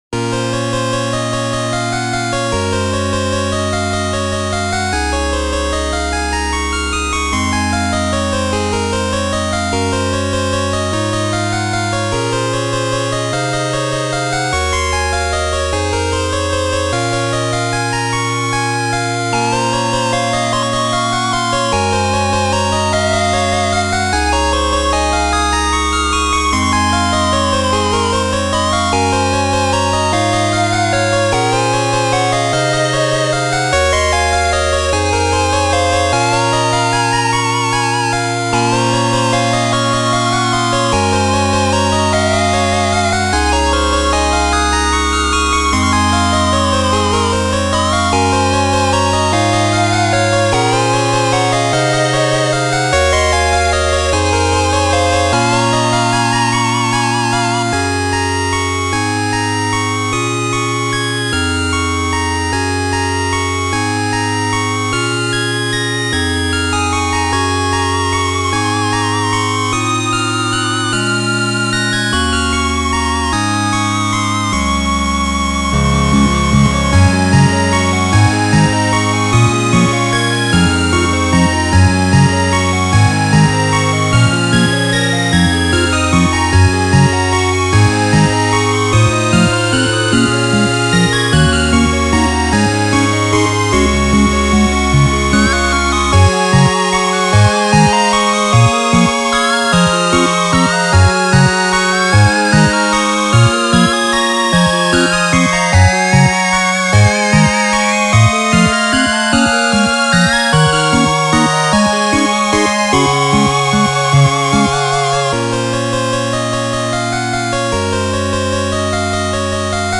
ジャンルファミコン風、8-Bit
BPM50
使用楽器8-Bit音源
原曲にある切なくも優しいイメージを崩さないように編曲しております。